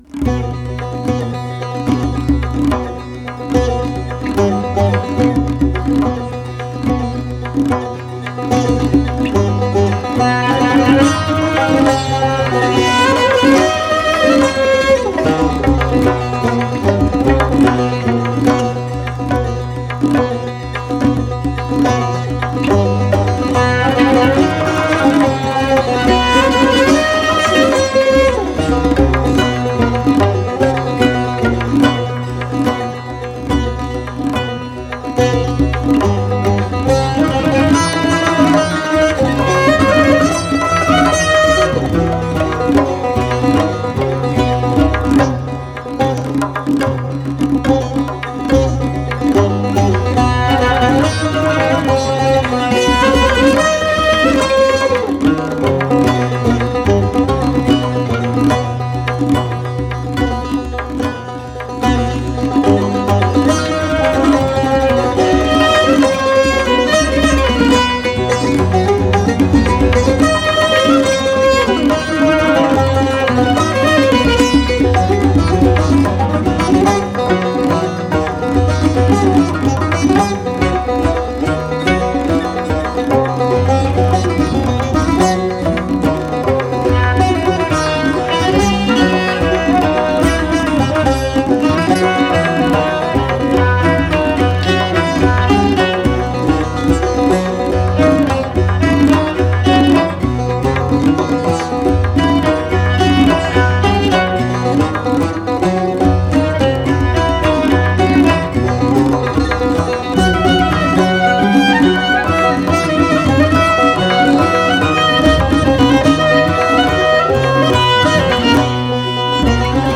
Chahar Mezrab Tasnif Chahargah